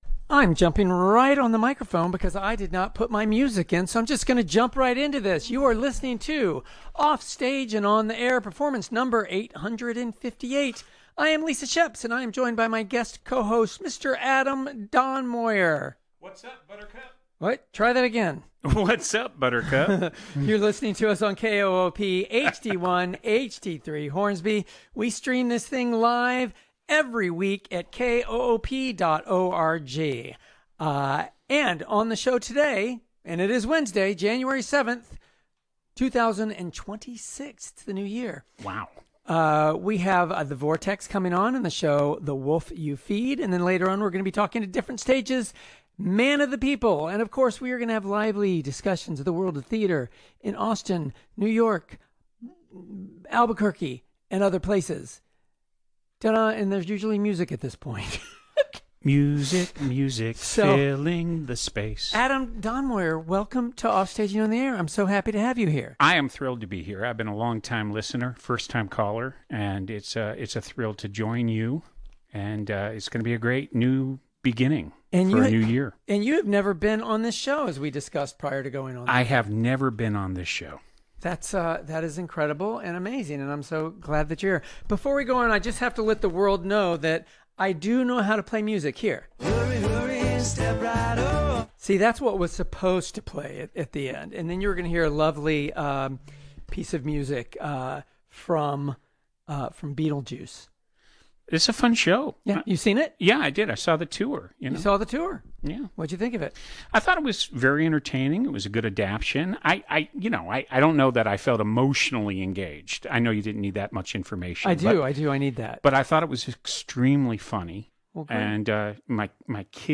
A lively and fun conversation about Theatre around the country and the local Austin Theatre Sce ne